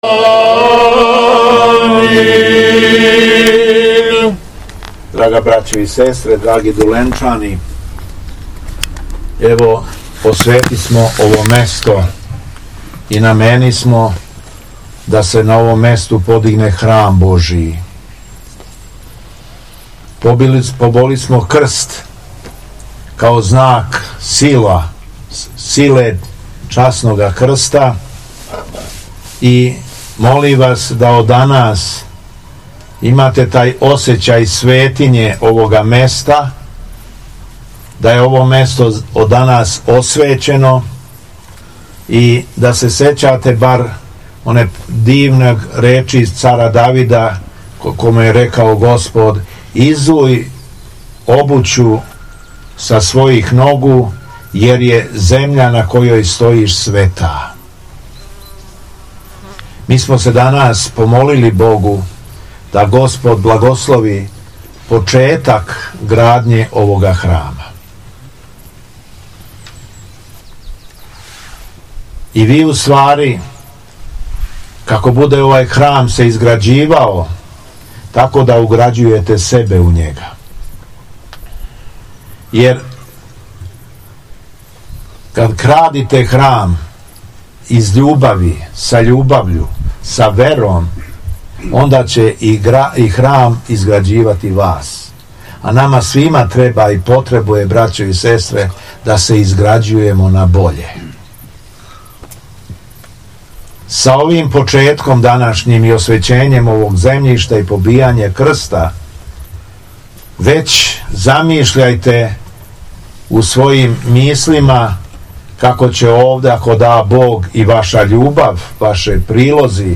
СВЕТА АРХИЈЕРЕЈСКА ЛИТУРГИЈА У МАНАСТИРУ РАЛЕТИНАЦ - Епархија Шумадијска
Беседа Његовог Преосвештенства Епископа шумадијског г. Јована - манастир Ралетинац